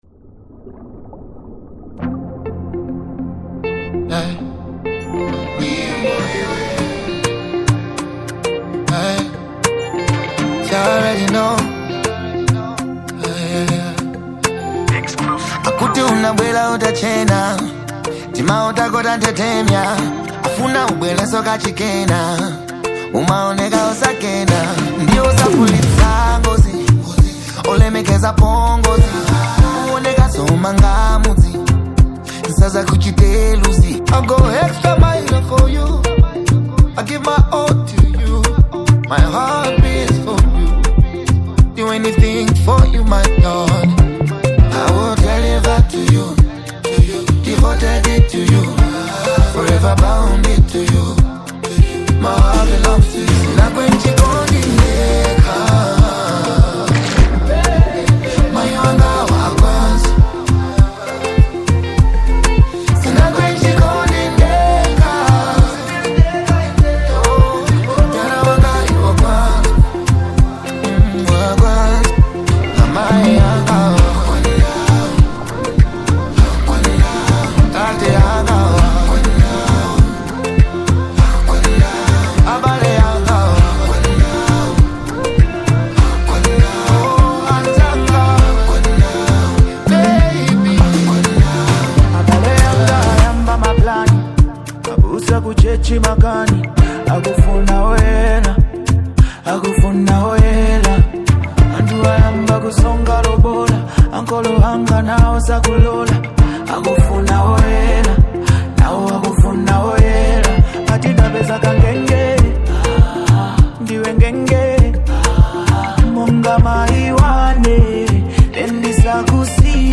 Afro-Beats